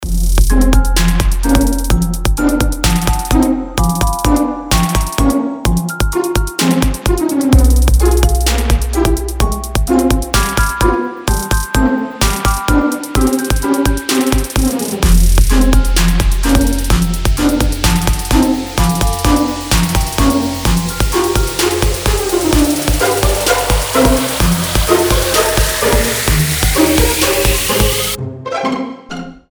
• Качество: 320, Stereo
Electronic
без слов
волшебные
колокольчики
рождественские